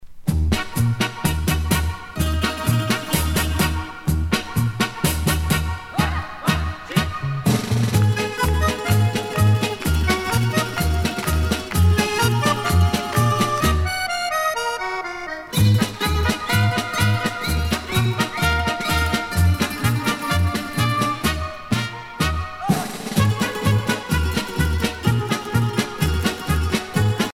danse : kazatchok
Pièce musicale éditée